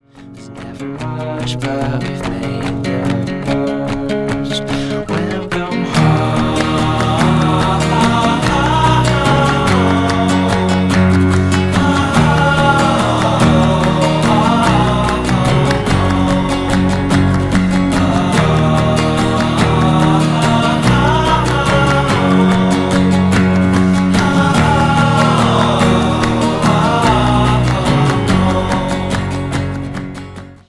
• Качество: 320, Stereo
гитара
грустные
красивый мужской голос
спокойные
indie pop
indie folk
dream pop
Indietronica